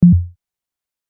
/ F｜システム電子音 / F-03 ｜システム3_キャンセル
ドッ